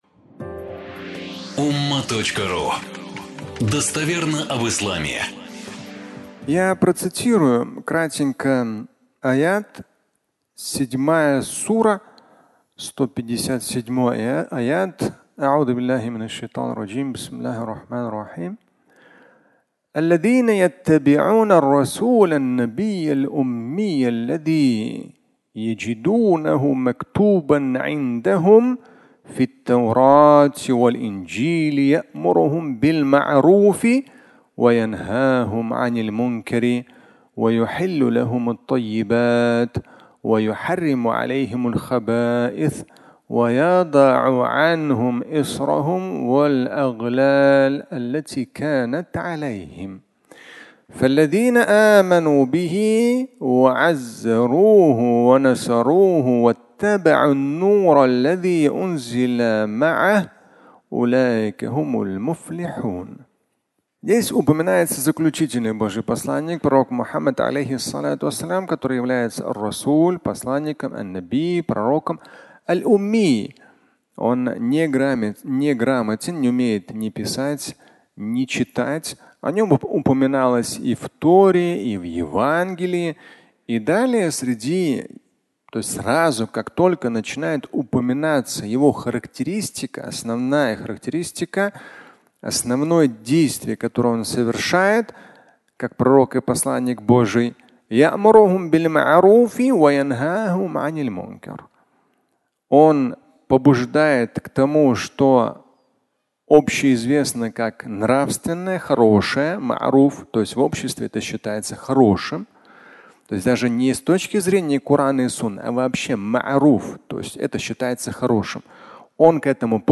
Плохое и скверное (аудиолекция)